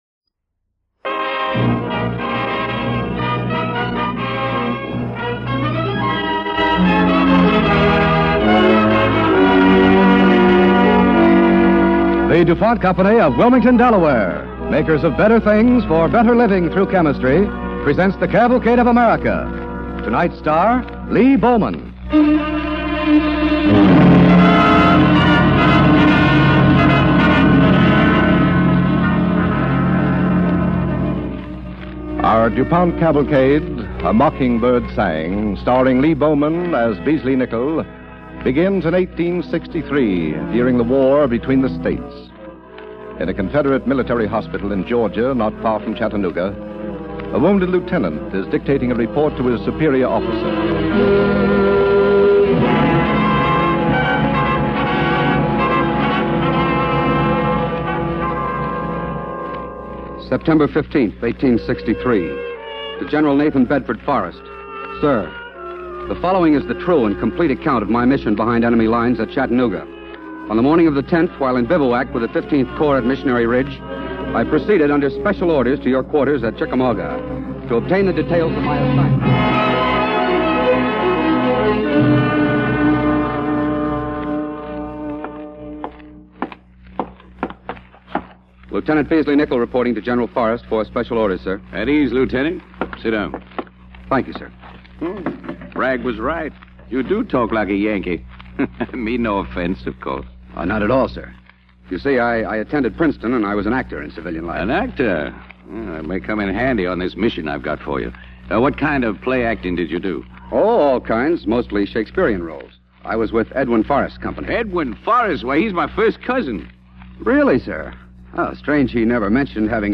starring Lee Bowman and Claudia Morgan
Cavalcade of America Radio Program